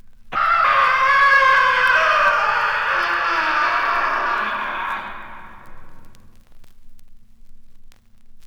• three men screaming.wav
three_men_screaming_ngb.wav